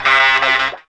0506R FUZGTR.wav